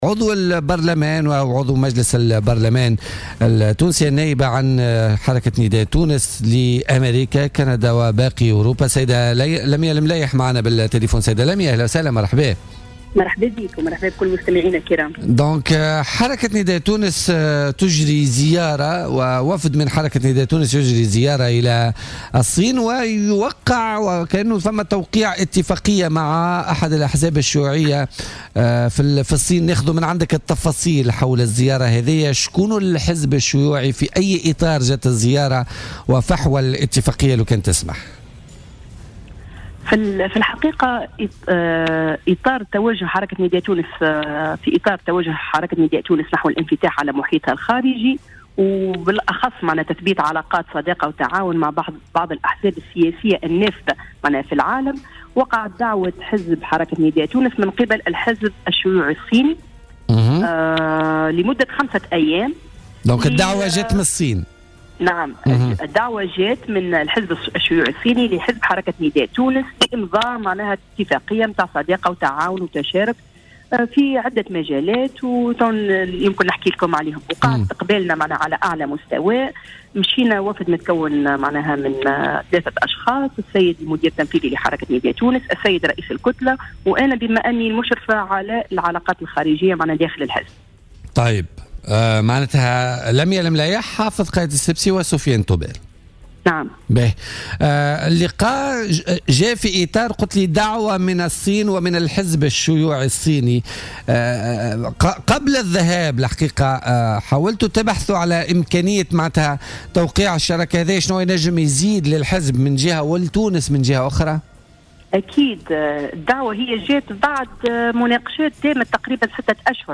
وقالت في اتصال هاتفي بـ "الجوهرة اف أم" عبر بوليتيكا، إن وفدا يمثّل نداء تونس ويتكون من حافظ قايد السبسي وسفيان طوبال بالاضافة إلى شخصها، تحوّل مؤخرا إلى الصين على اثر دعوة تلقاها من الحزب الصيني وتوجت هذه الزيارة بتوقيع مذكرة تفاهم وصداقة وشراكة مع الحزب الشيوعي الصيني الذي تكمن اهميته في دوره في انجاح التجربة الاقتصادية الريادية للصين لتصبح ثاني قوة اقتصادية في العالم. وأكدت النائبة على ضرورة الاستفادة من التجربة الصينية من الجانب الاقتصادي خاصة في ظل الظرف الاقتصادي الصعب الذي تمر به تونس. كما أشارت إلى المشروع الصيني الضخم المعروف بطريق الحرير الذي سيربط دول العالم.